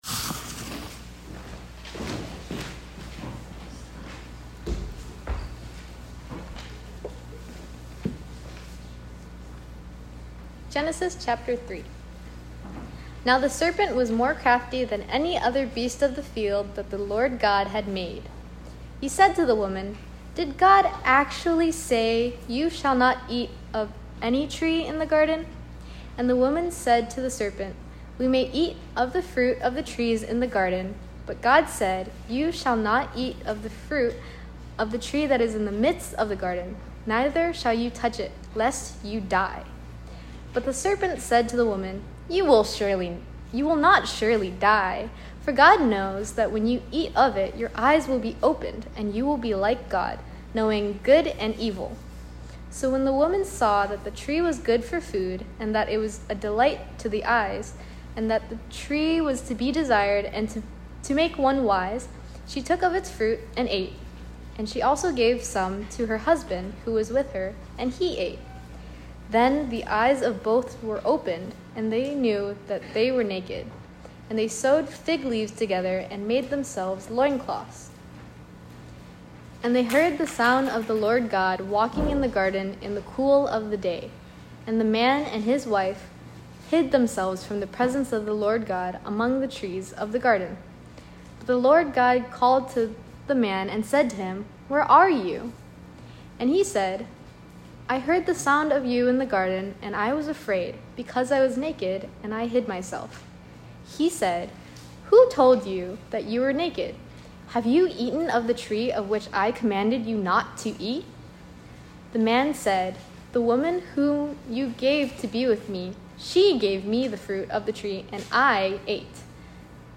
THE FALL (Genesis 3 Sermon) Preached on 9/21/25 at Cross of Christ Fellowship in Naperville